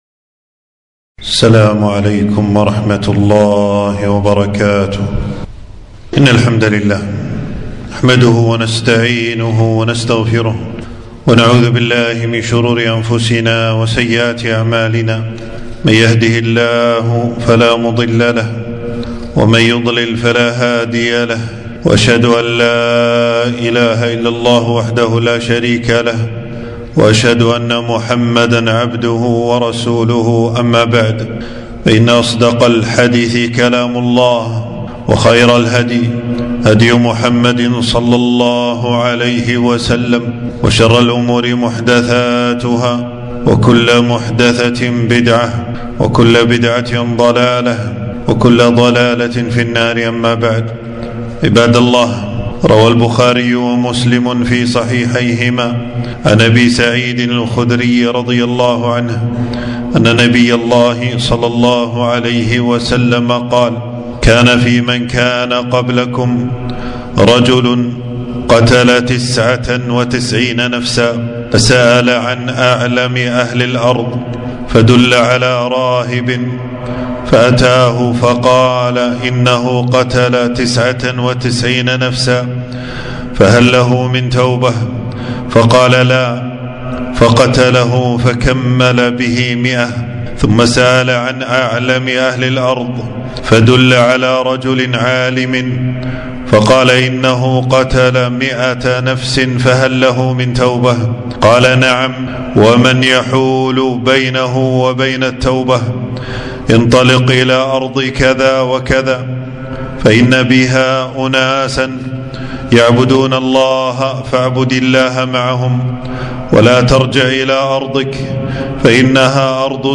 خطبة - قصة الرجل الذي قتل تسعة وتسعين نفسا وما فيها من فوائد